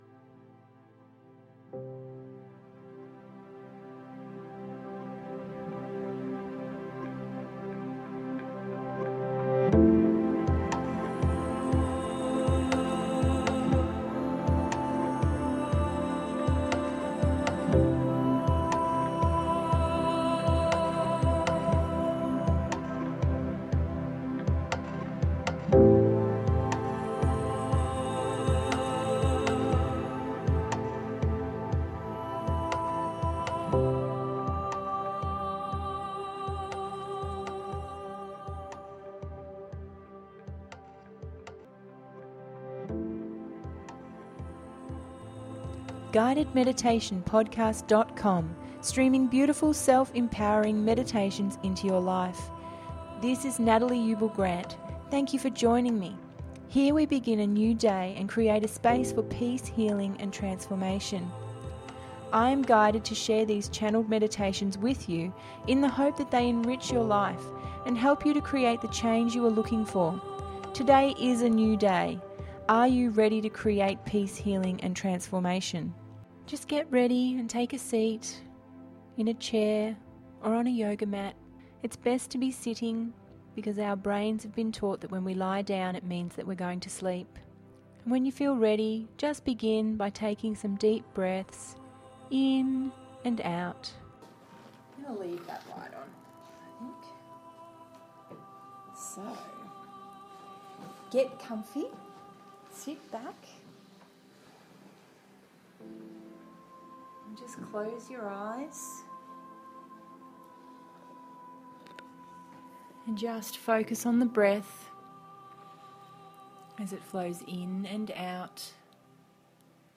Embodying Crystal Light Energy Part 2…057 – GUIDED MEDITATION PODCAST